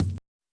kick02.wav